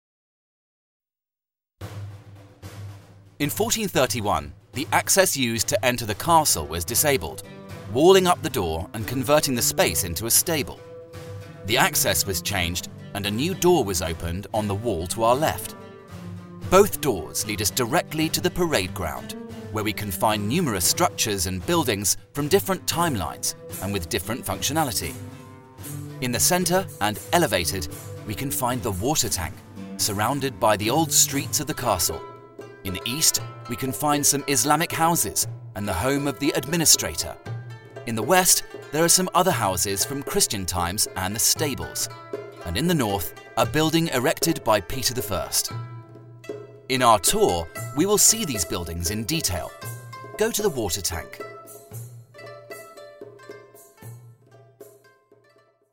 Ruta audioguiada